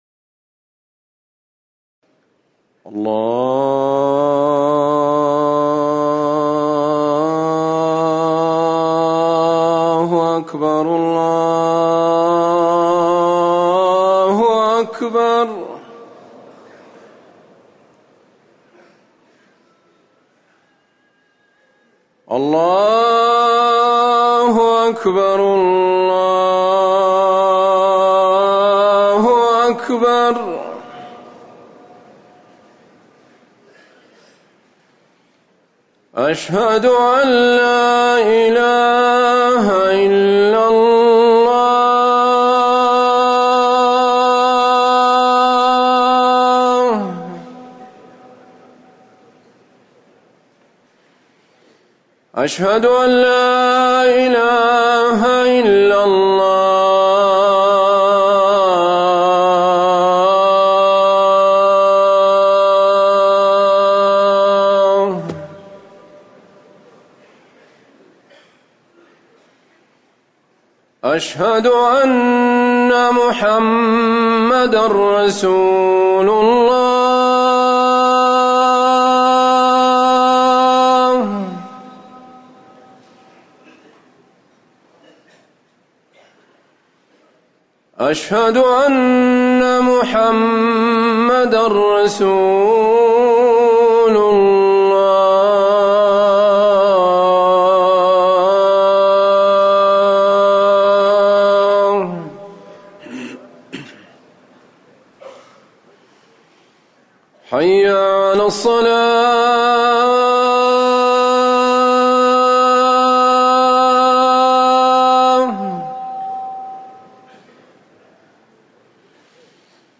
أذان الفجر الثاني
تاريخ النشر ١٢ محرم ١٤٤١ هـ المكان: المسجد النبوي الشيخ